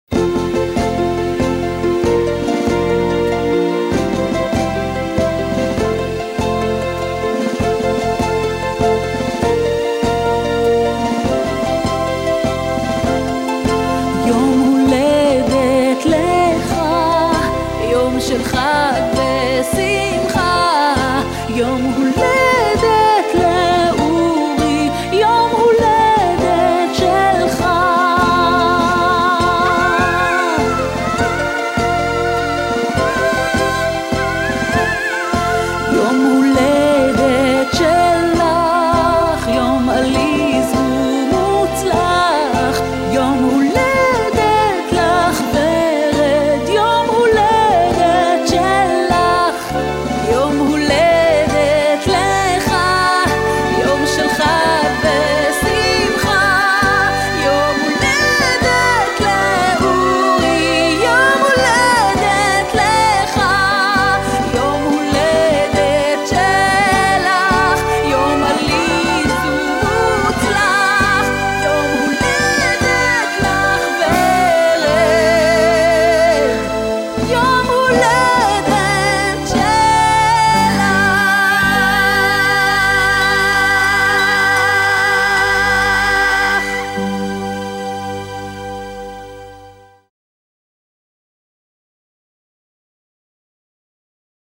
(שירת נשים)